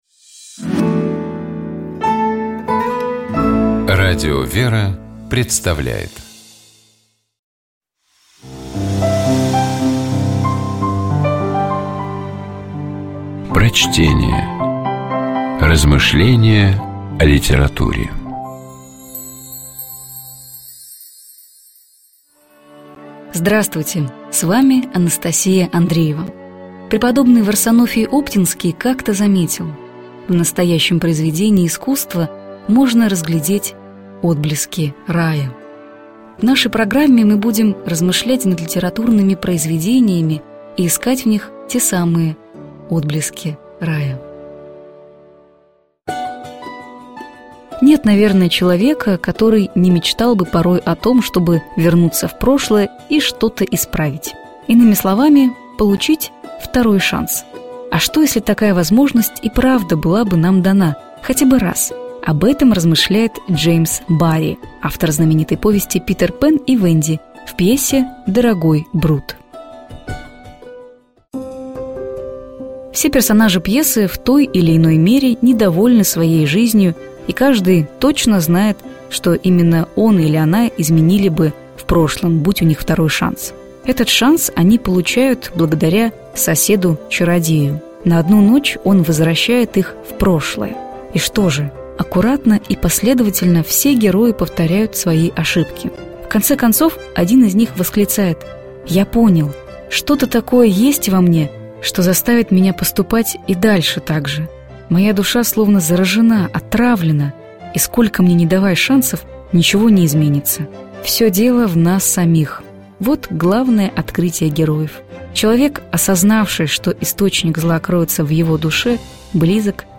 Prochtenie-D_-Barri-Dorogoj-Brut-O-vtoryh-shansah.mp3